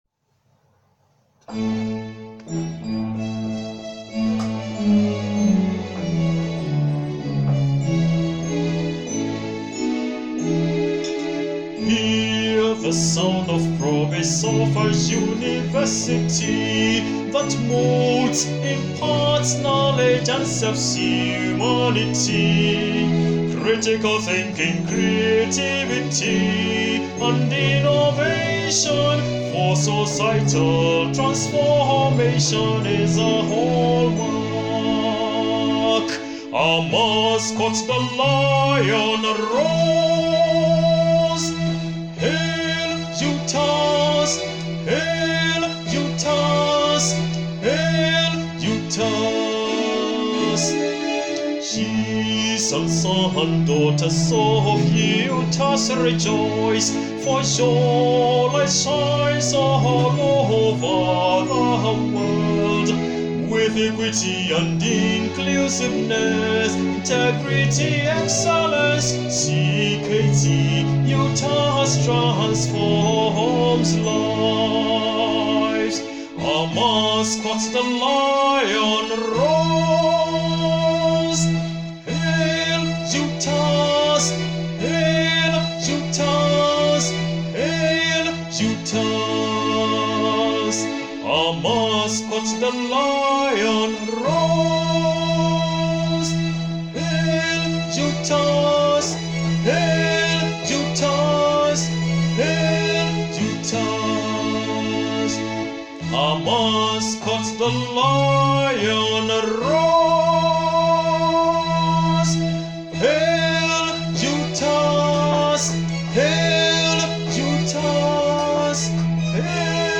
University Anthem
cktutas-official-Anthem.aac